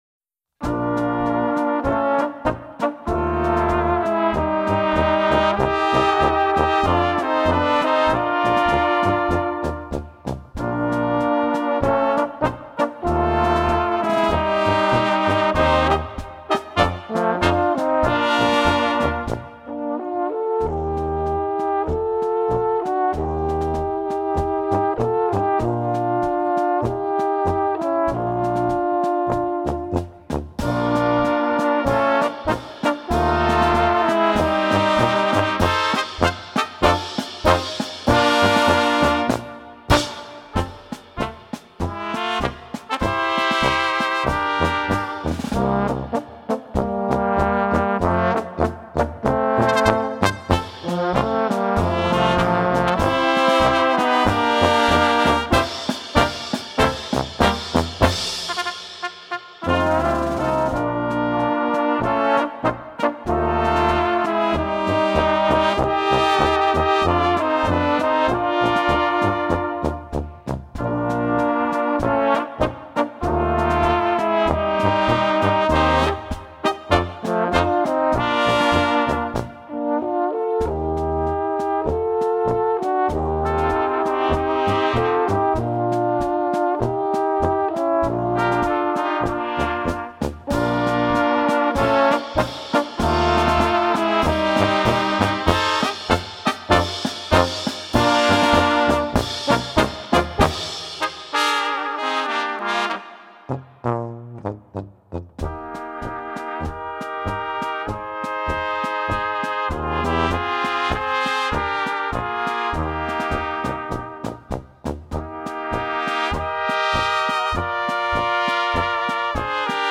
Gattung: Polka für Böhmische Besetzung
Besetzung: Kleine Blasmusik-Besetzung
1.Flügelhorn in B
Trompete B
Tenorhorn B
Tuba B/C
Schlagzeug